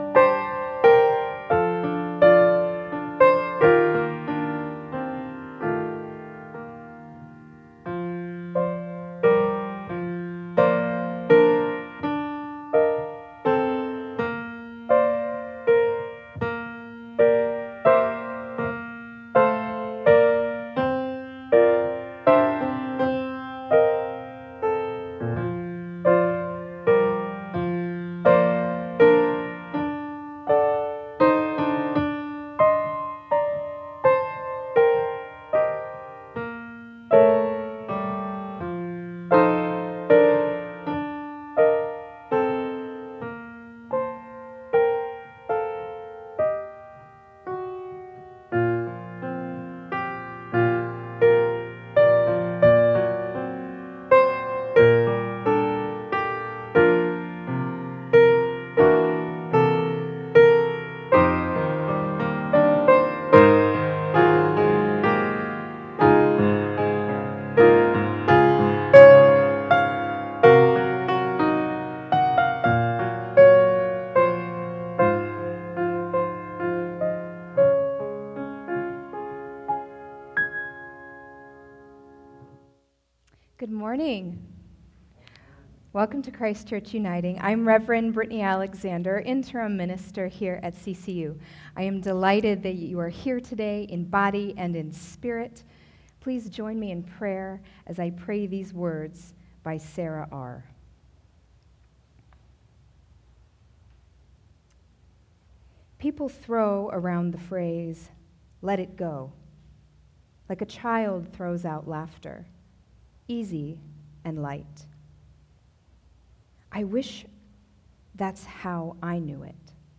Full worship service: April 3, 2022 (Final Sunday in Lent)